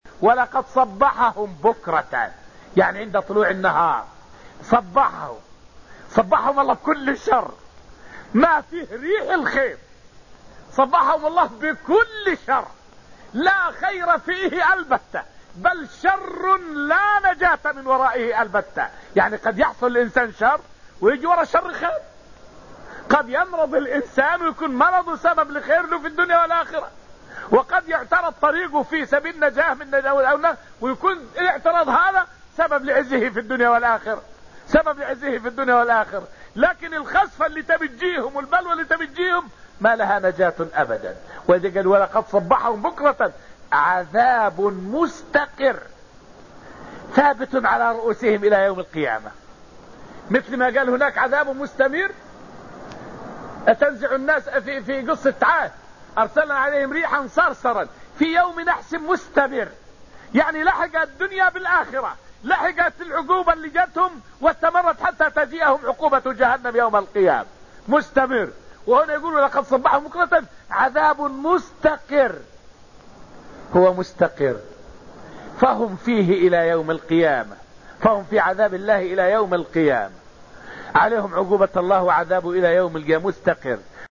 فائدة من الدرس السابع من دروس تفسير سورة القمر والتي ألقيت في المسجد النبوي الشريف حول إنزال عذاب الله بالكافرين صباحًا.